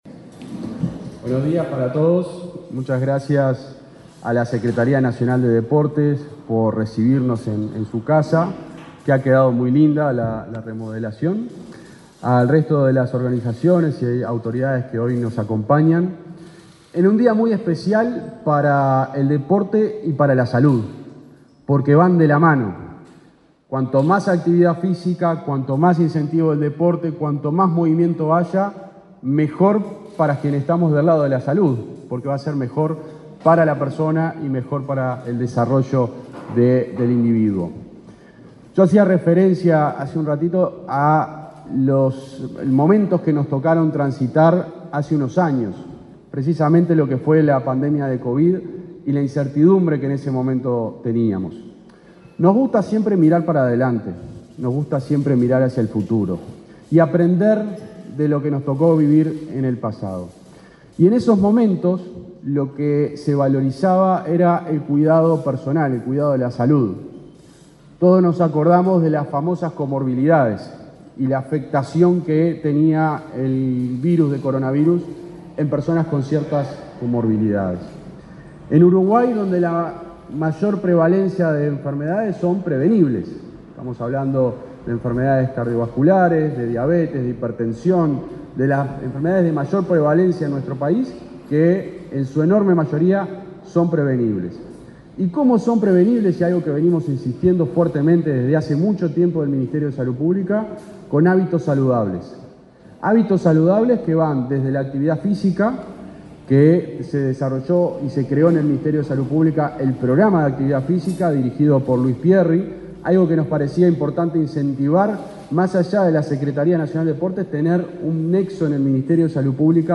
Palabra de autoridades en acto en la SND
El subsecretario de Salud Pública, José Luis Satdjian, y el secretario nacional del Deporte, Sebastián Bauzá, participaron, este lunes 8 en Montevideo